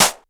snr_61.wav